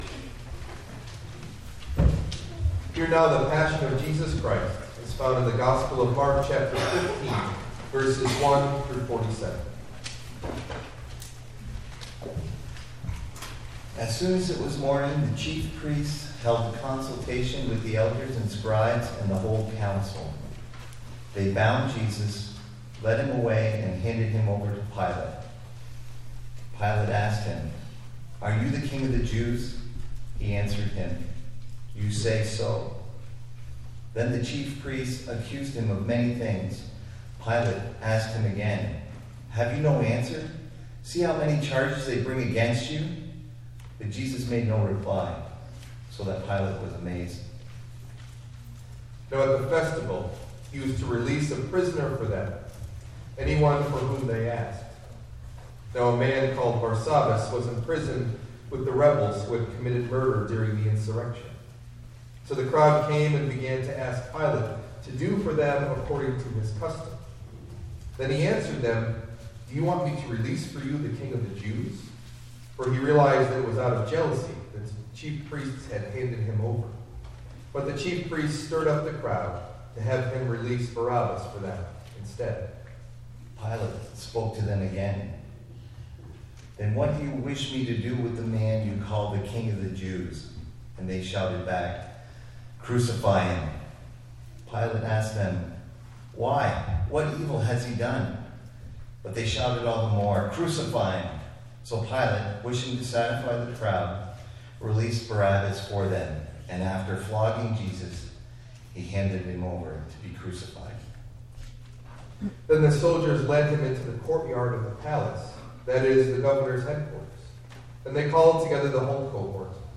Meditation Delivered at: The United Church of Underhill (UCC and UMC)